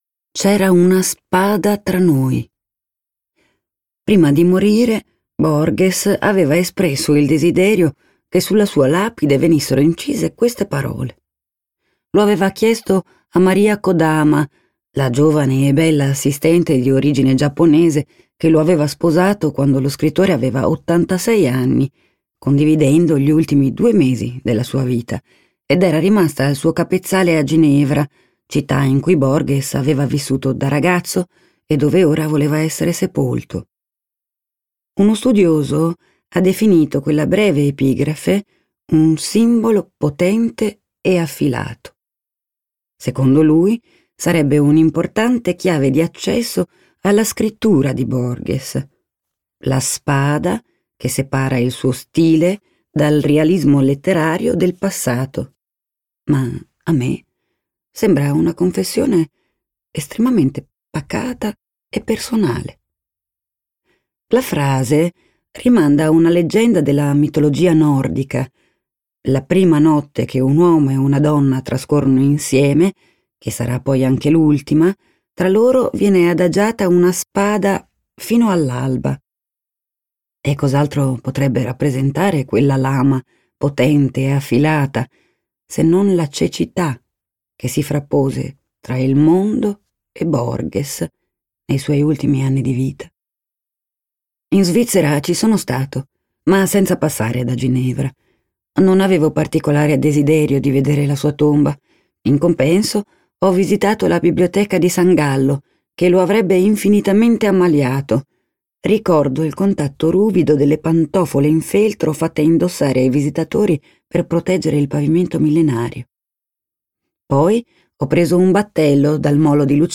letto da Valentina Bellè
Versione audiolibro integrale